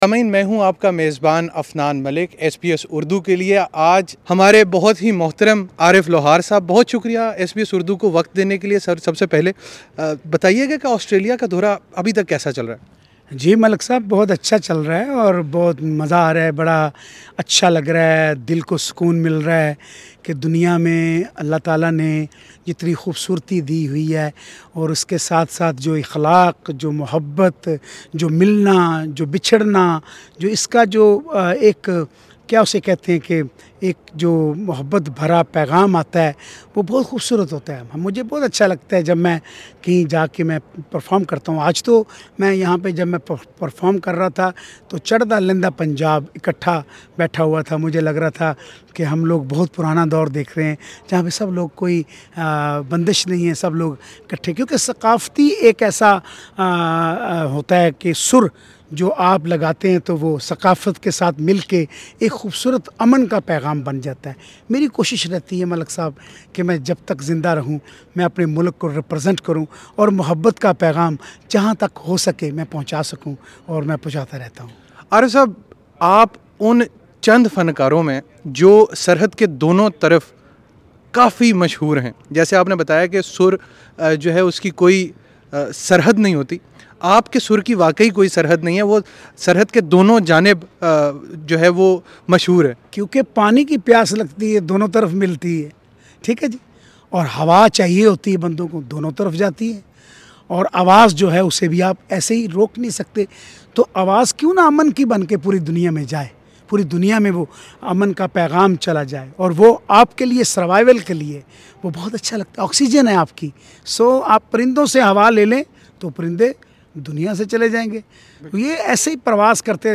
ایس بی ایس اردو سے خصوصی گفتگو کرتے ہوئے عارف لوہار کا کہنا تھا کہ انہیں آسٹریلیا میں پاکستان اور ہندوستان دونوں اطراف کے لوگوں سے بے پناہ محبت ملی۔